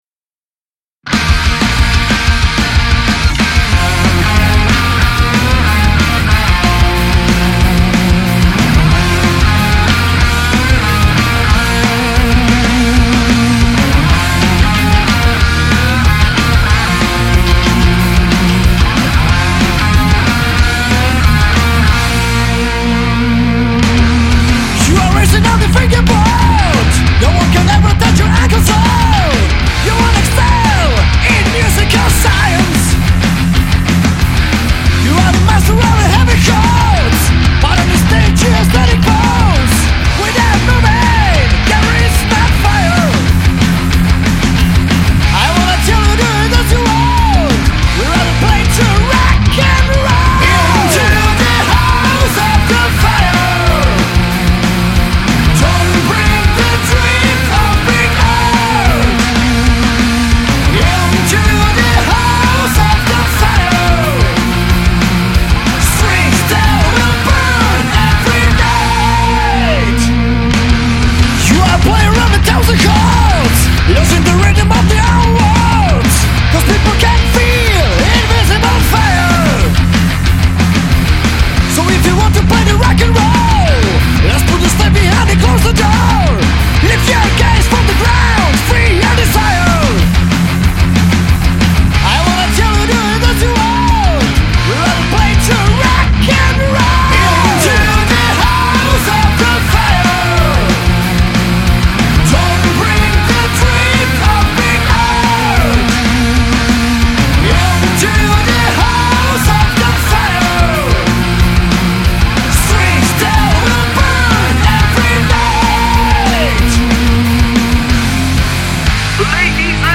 metal-metal / Jablunkov